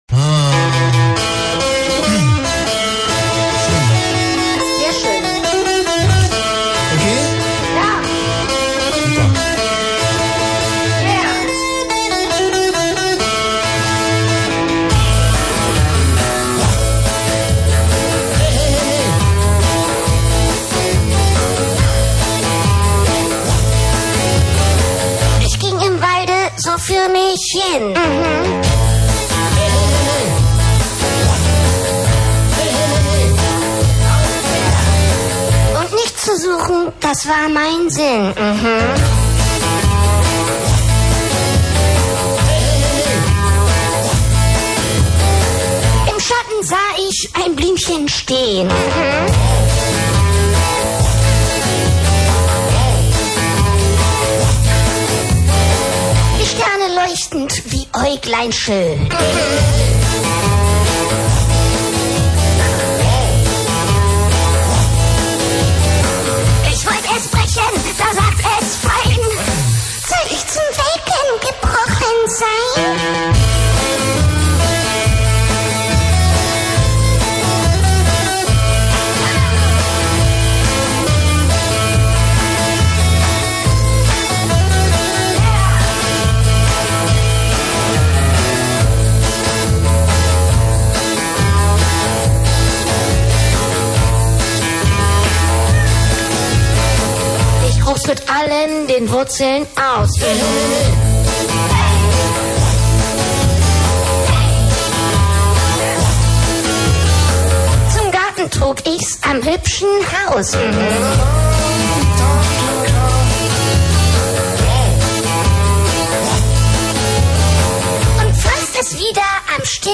mono
als Rockabillität